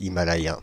Ääntäminen
Paris: IPA: [i.ma.la.jɛ̃]